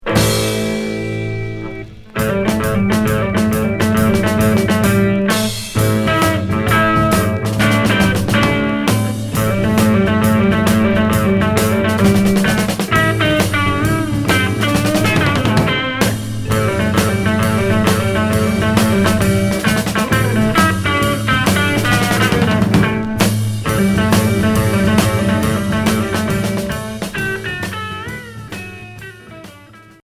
Heavy rock psychédélique Unique 45t retour à l'accueil